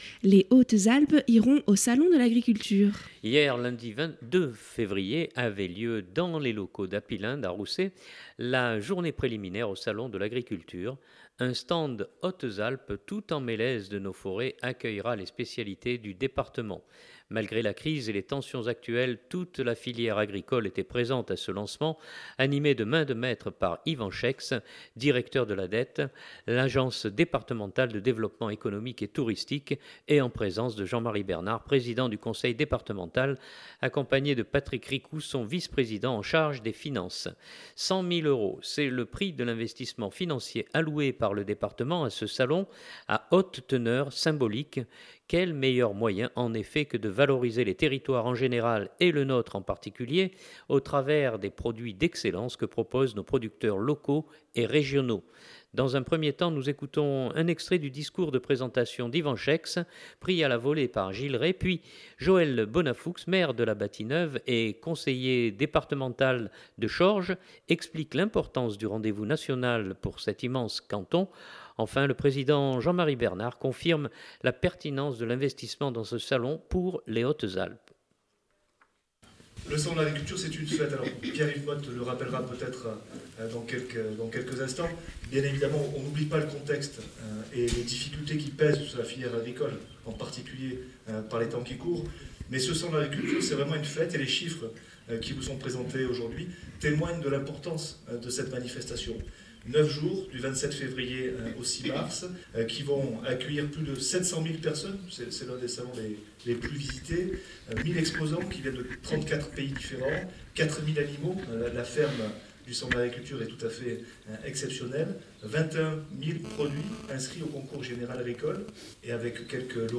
Enfin, le Président Jean-Marie Bernard, confirme la pertinence de l’investissement dans ce salon pour les Hautes-Alpes.